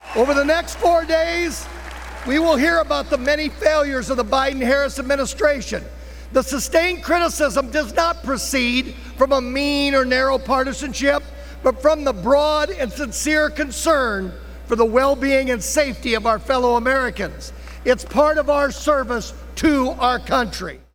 Iowa G-O-P chairman Jeff Kaufmann was chosen to kick off the process with a nominating speech.